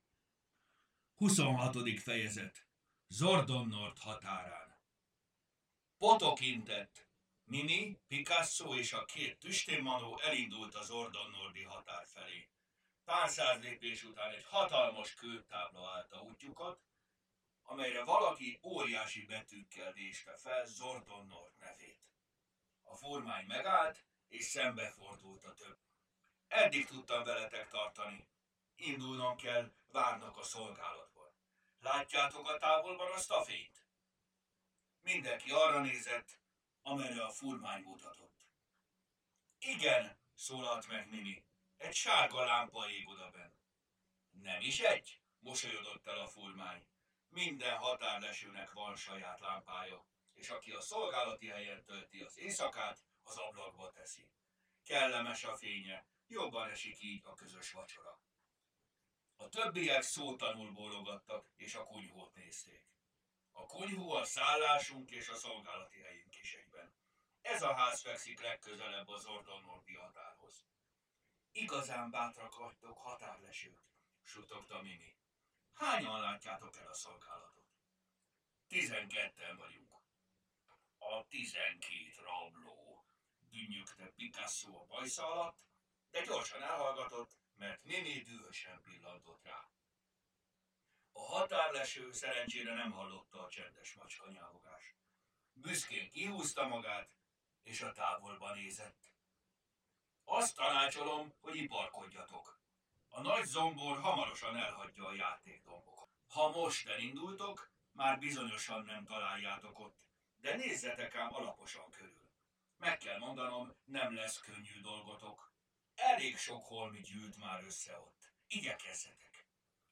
Hangos mese: Zordonnord határán Mindet meghallgatom ebből a folyamból!